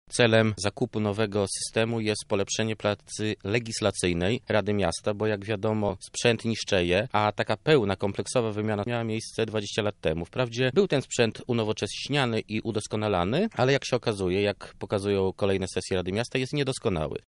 – tłumaczy radny